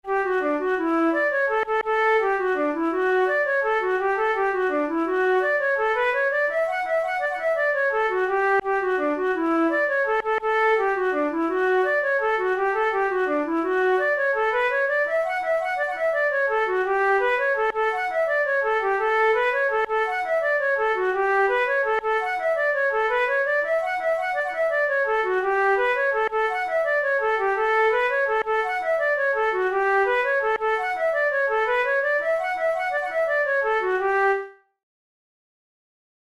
Traditional Irish jig
Categories: Jigs Traditional/Folk Difficulty: intermediate